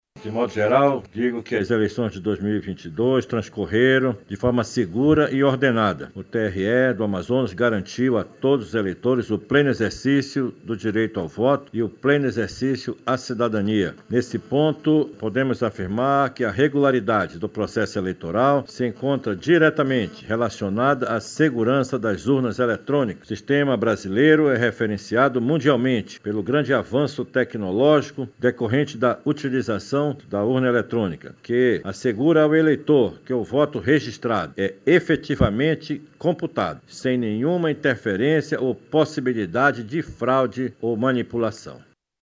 Durante coletiva de imprensa, órgãos envolvidos nas Eleições 2022 divulgaram dados.
Sonora-desembargador-Jorge-Luis-presidente-do-Tribunal-Regional-Eleitoral-do-Amazonas.mp3